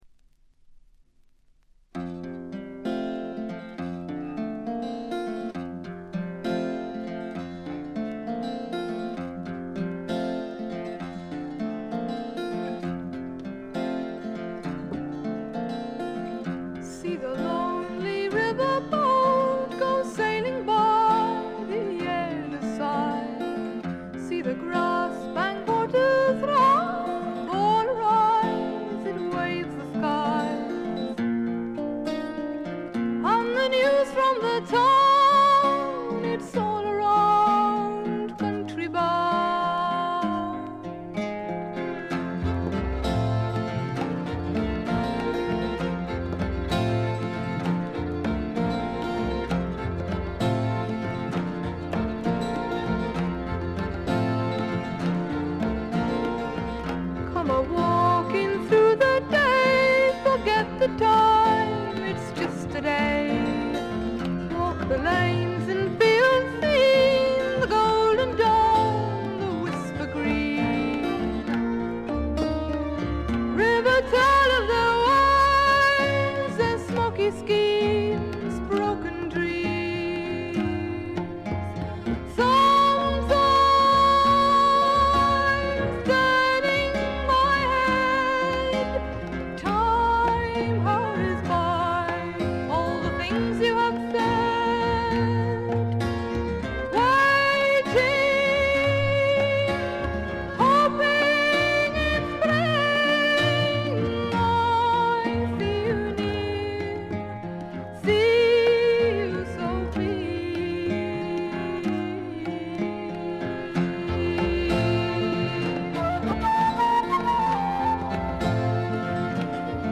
試聴曲は現品からの取り込み音源です。
Mandolin, Violin, Vocals